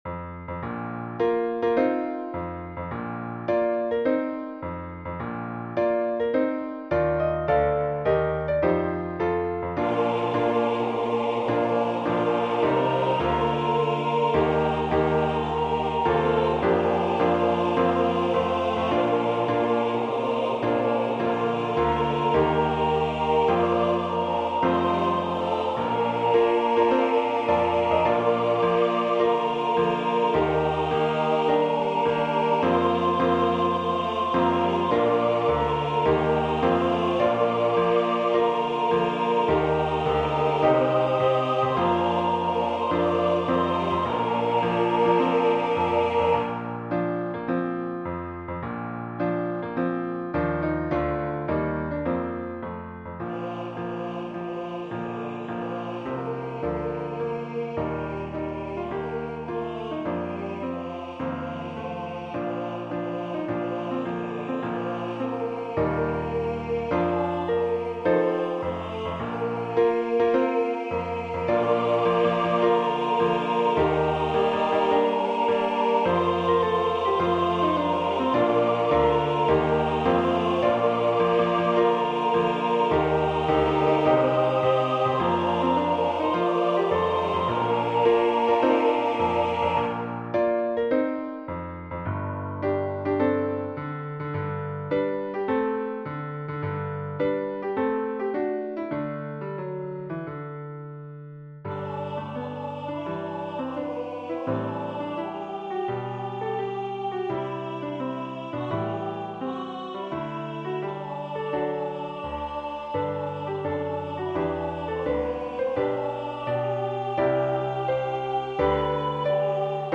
SATB with Piano Accompaniment with 4th verse Congregation and Organ joining. There is also a descant/oligato part to be sung on the 4th verse.
SATB , Organ/Organ Accompaniment
Choir with Congregation together in certain spots